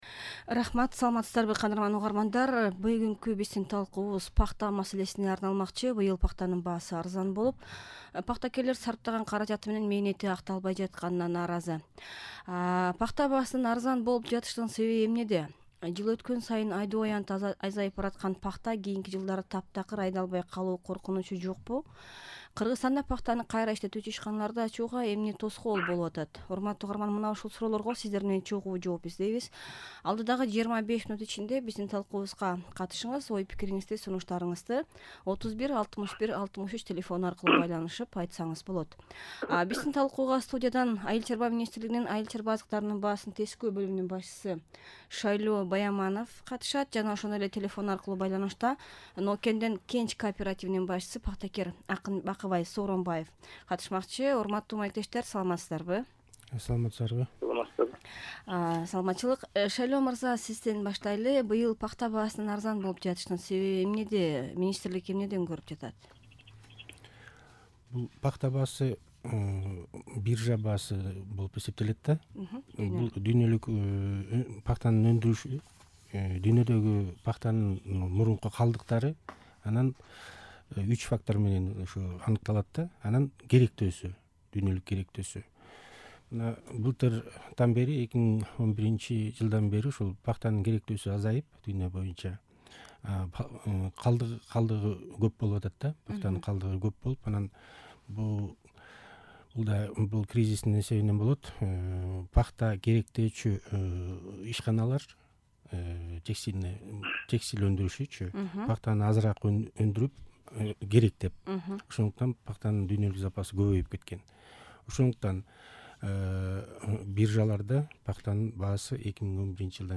Пахта тууралуу талкуу (1-бөлүк)